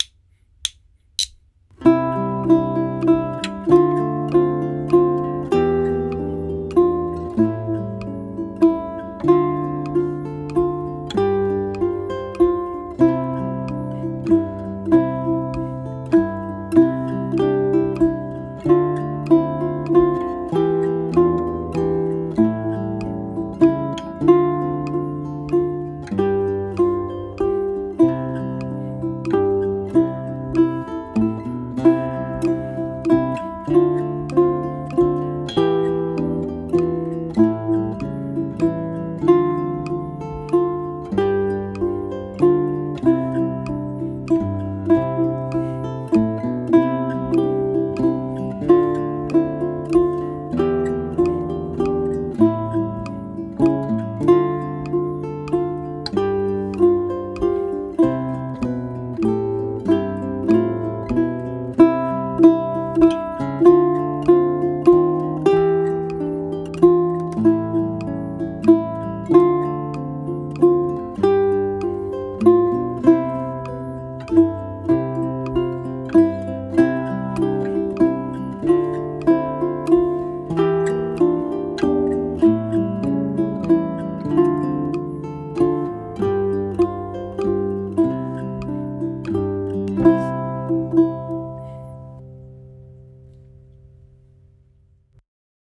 A lullaby is a cradle song: a mellow tune sung to lull a child to sleep. Like Walkin', Lullaby uses three notes on the second string: E, F and G. Be mindful of the triple meter feel (strong-weak-weak).
ʻukulele